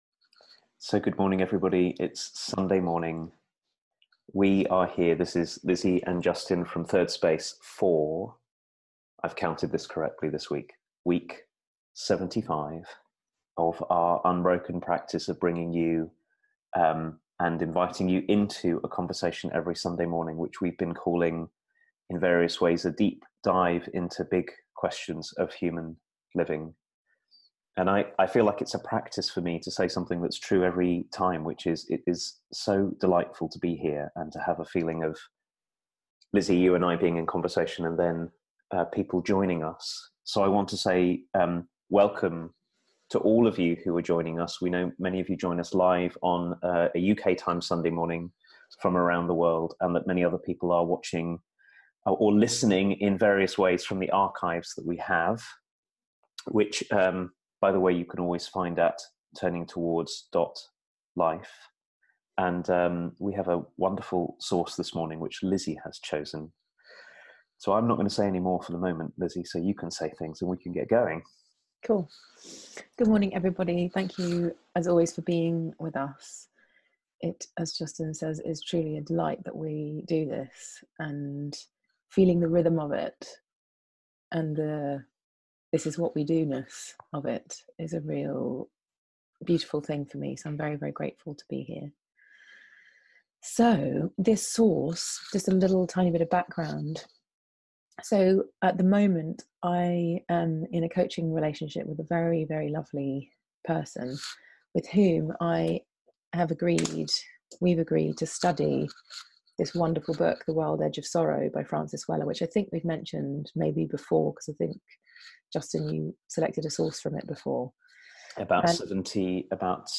How our welcoming ourselves makes it possible for us to welcome others more fully, without having to control them or judge them. And what comes from welcoming even our own shame. A conversation